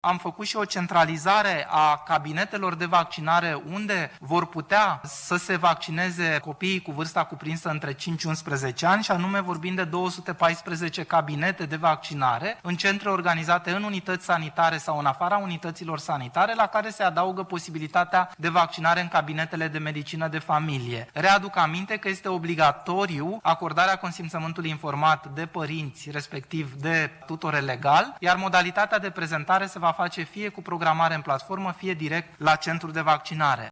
Între timp, continuă pregătirile pentru vaccinarea copiilor cu vârsta cuprinsă între 5 şi 11 ani. Platforma de programare şi registrul electronic naţional au fost optimizate şi vor fi deschise 214 de centre de vaccinare, a anunţat coordonatorul campaniei de vaccinare, Valeriu Gheorghiţă: